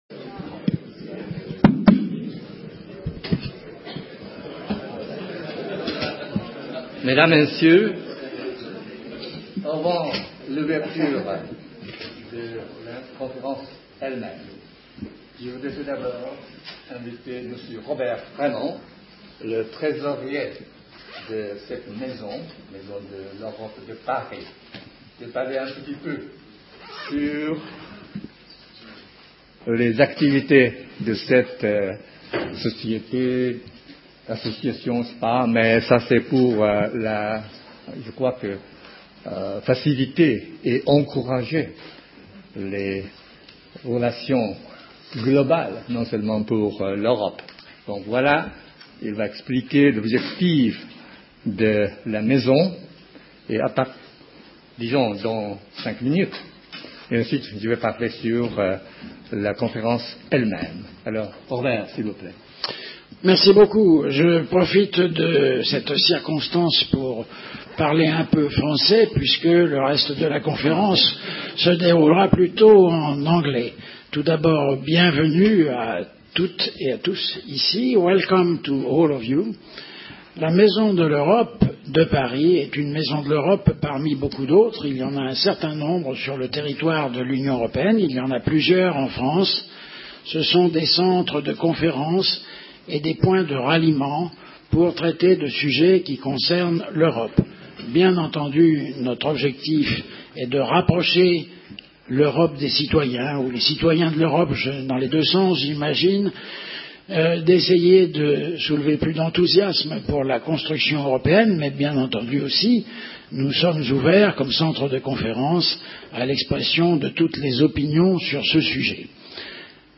A conference organised by the International Economic Policy Studies Association in co-operation with La Maison de l'Europe de Paris in Paris on 23rd September 2013